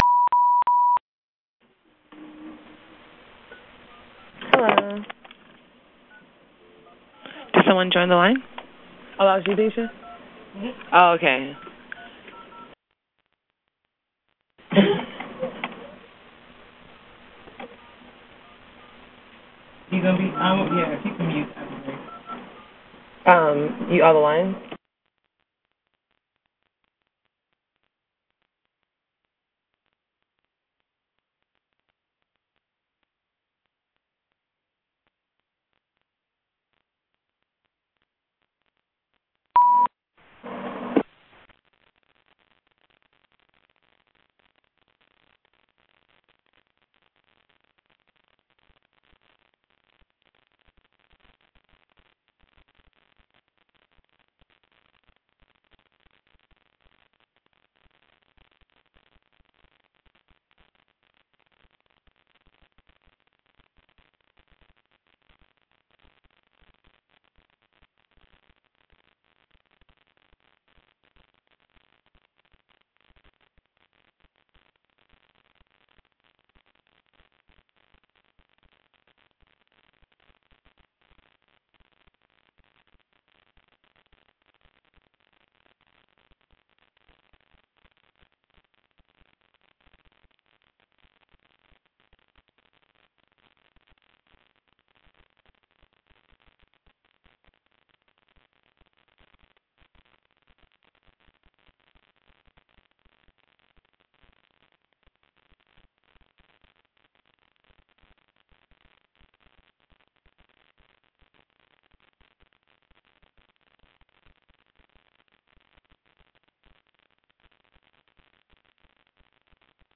Brown Bag Lunches > Webinars